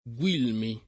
[ gU& lmi ]